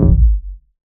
Metro Kicks [Rnb].wav